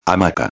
hamaca.mp3